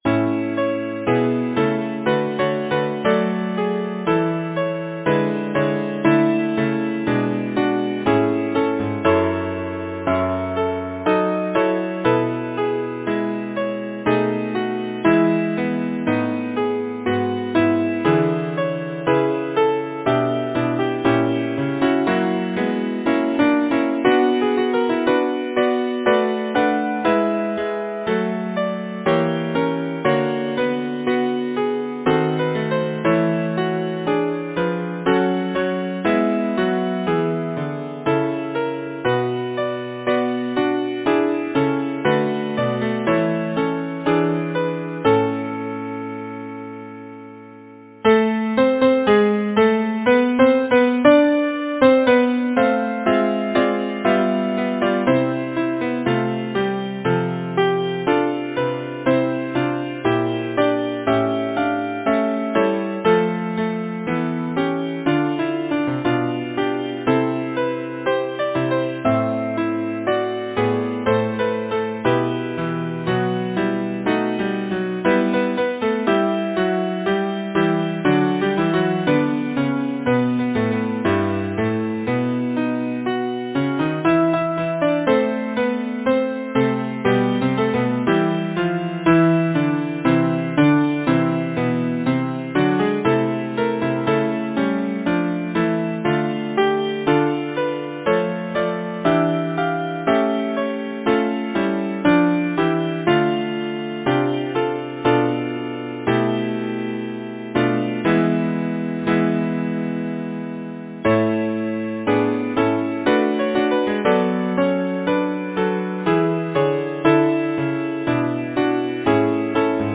Title: Autumn Composer: George Tootell Lyricist: Henry Wadsworth Longfellow Number of voices: 4vv Voicing: SATB Genre: Secular, Partsong
Language: English Instruments: A cappella